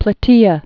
(plə-tēə)